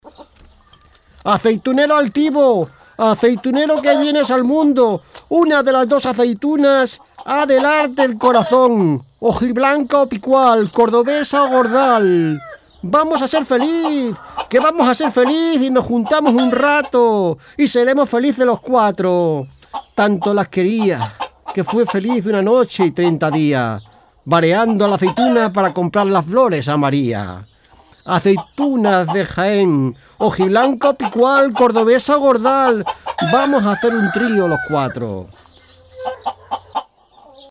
locutor, voiceover
guiones-humor-aceitunas-locutor-voiceover.mp3